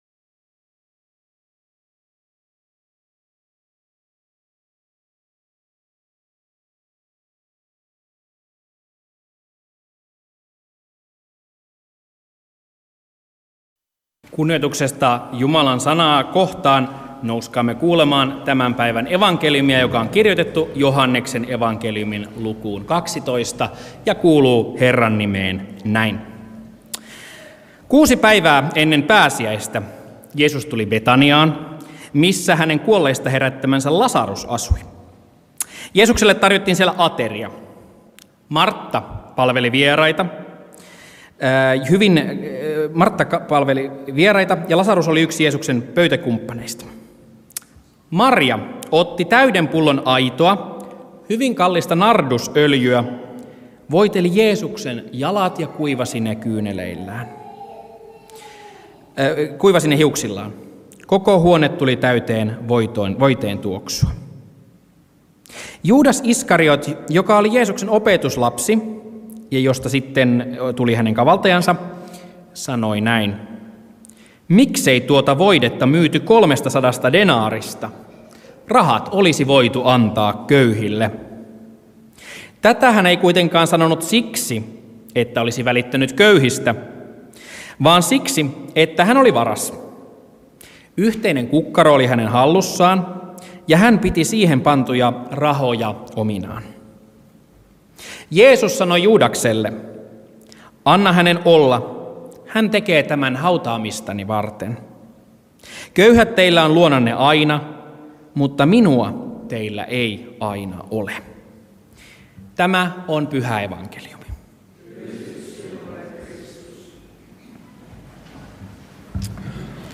Lahden Luther-kirkossa palmusunnuntaina Tekstinä Joh. 12: 1-8